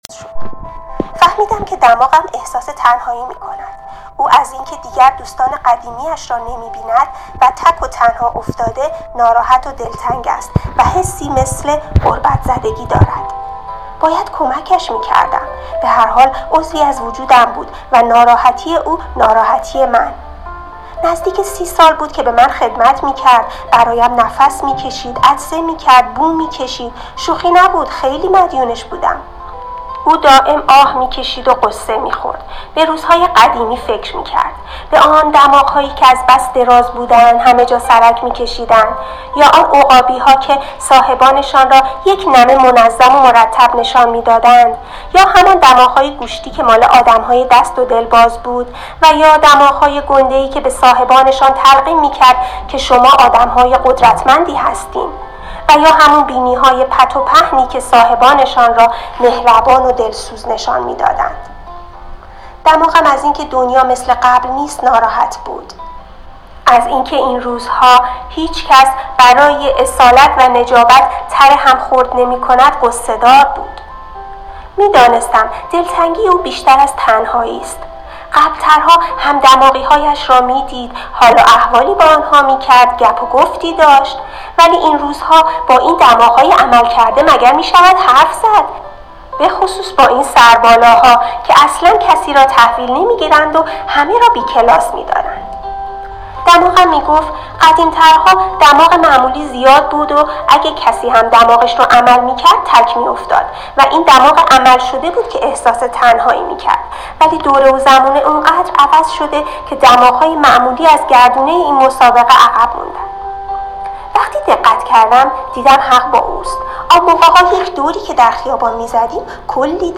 داستان خلاق صوتی